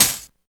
85 DIRTY HAT.wav